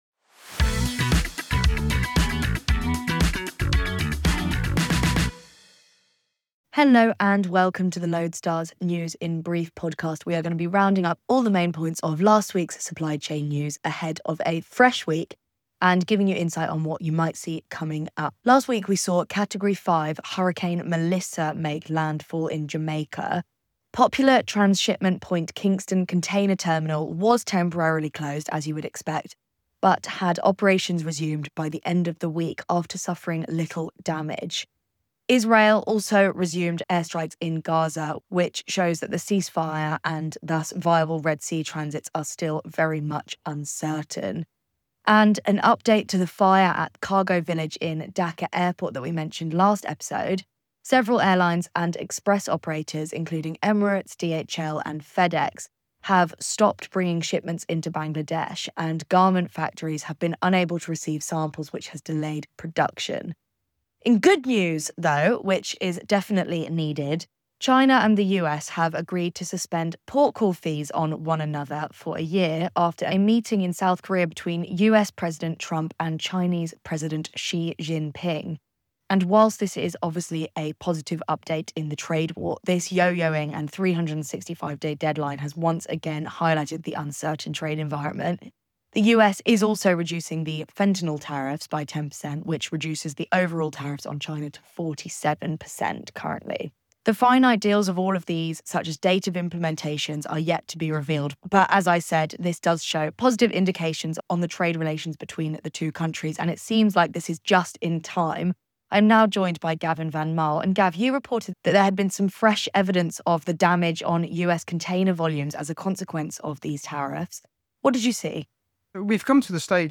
Meanwhile, hopes for Red Sea stability were dashed as Israel resumed airstrikes in Gaza.&nbsp; Host and news reporter